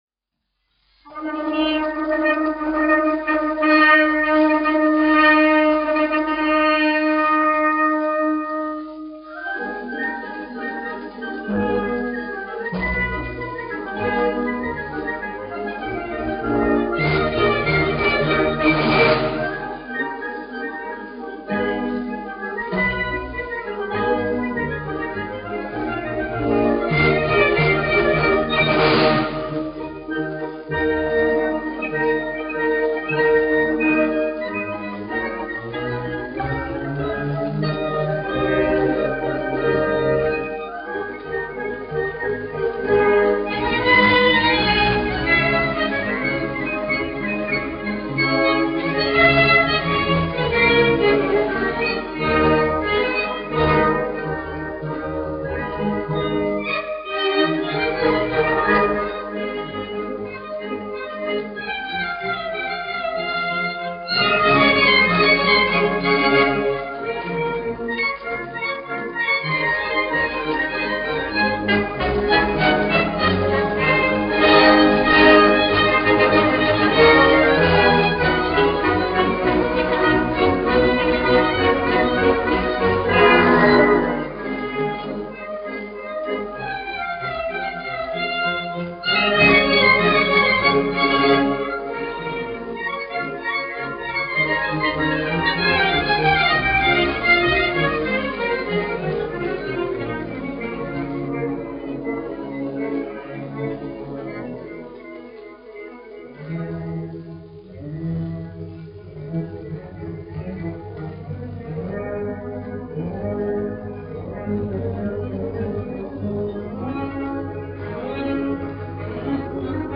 1 skpl. : analogs, 78 apgr/min, mono ; 25 cm
Orķestra mūzika
Marši
Skaņuplate